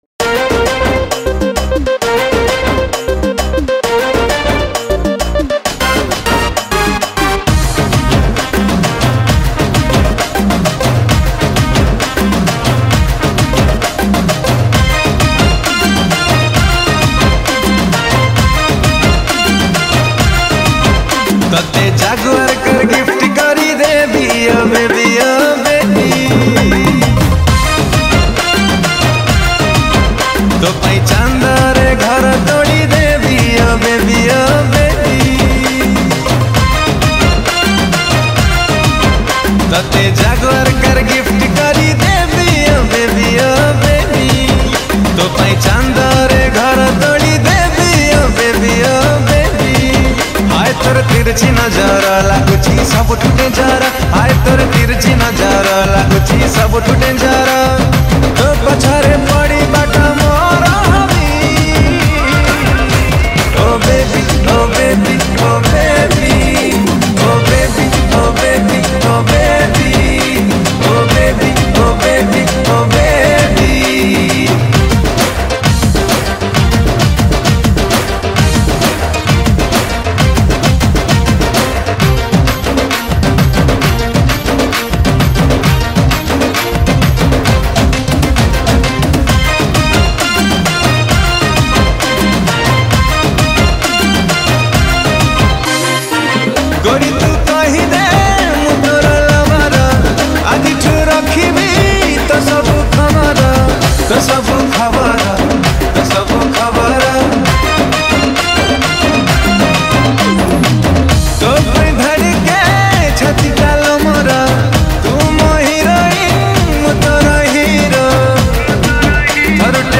Odia Masti Dance Song